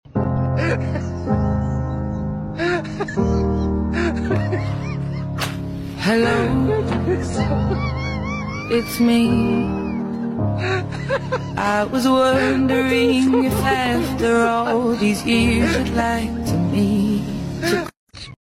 The number you have called sound effects free download